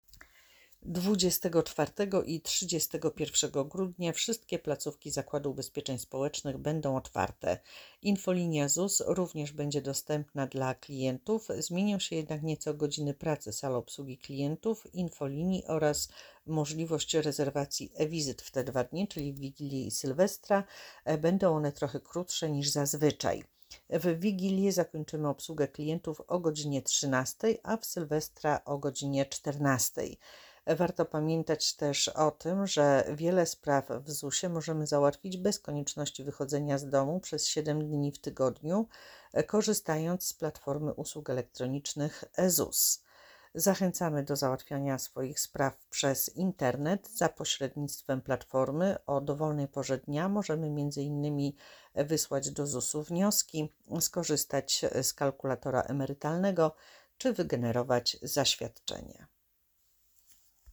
Audiodeskrypcja_007.mp3